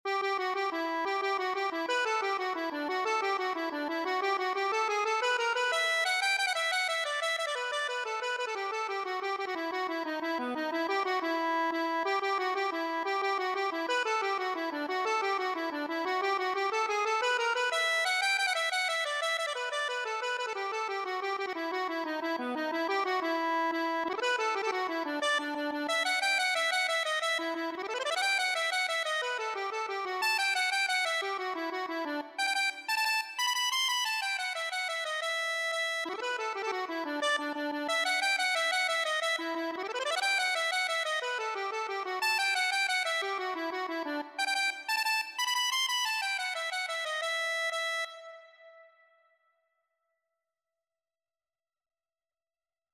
6/8 (View more 6/8 Music)
Allegretto .=120
E minor (Sounding Pitch) (View more E minor Music for Accordion )
Accordion  (View more Easy Accordion Music)
Traditional (View more Traditional Accordion Music)